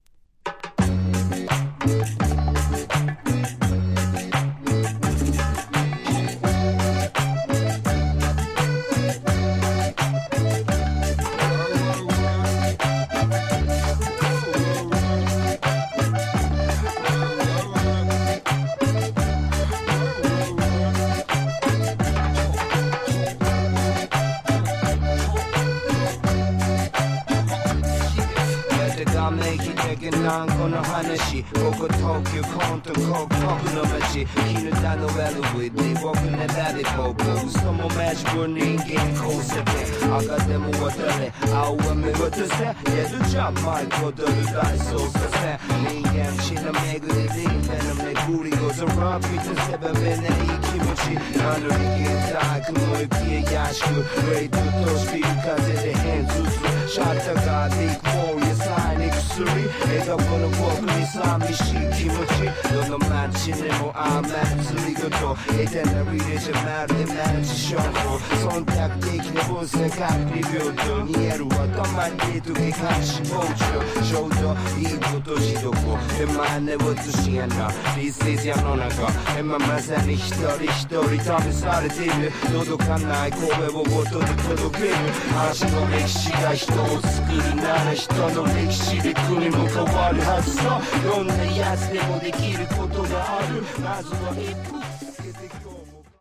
Tags: Cumbia , Japan , Colombia